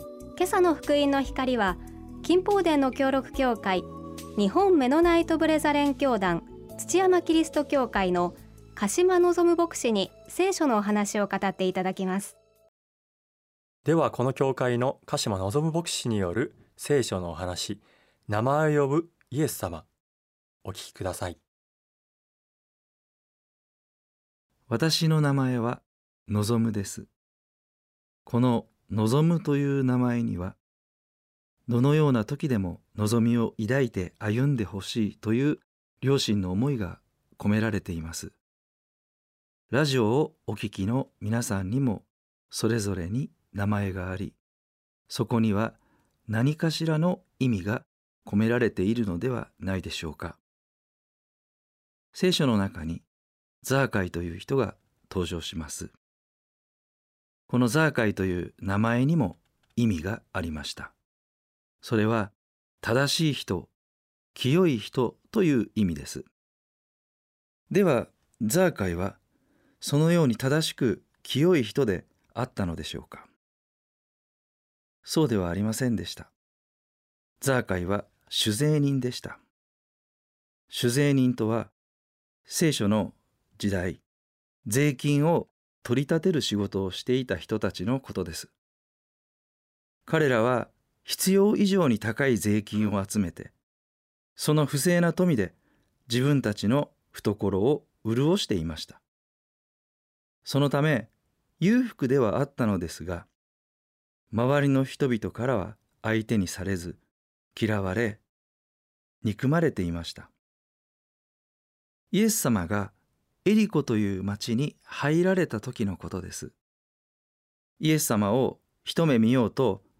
聖書のお話